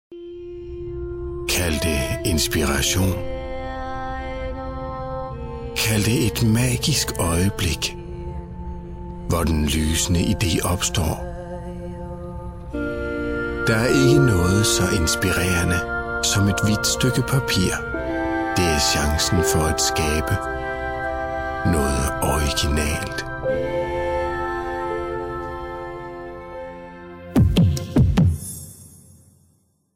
Danish voice over from a leading voice over artist - Native from Denmark
Sprechprobe: eLearning (Muttersprache):